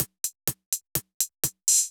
UHH_ElectroHatC_125-01.wav